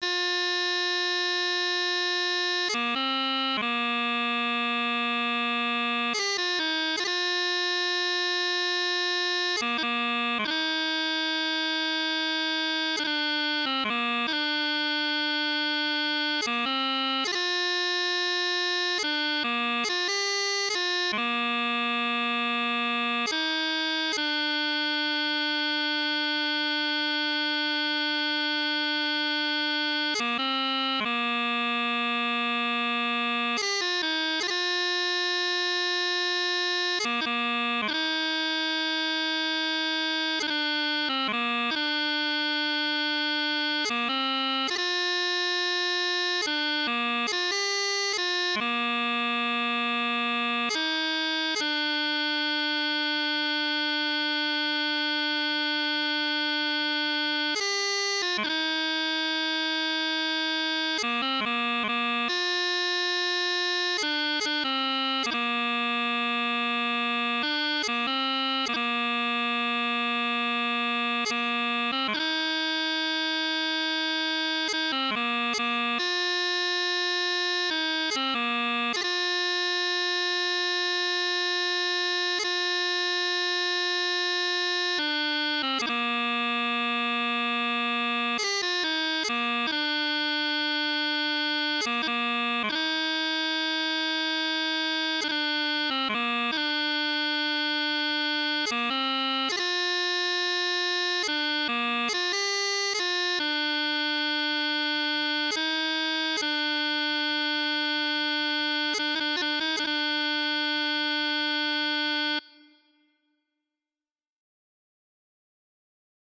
Air